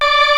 Index of /90_sSampleCDs/Giga Samples Collection/Organ/MightyWurltzBras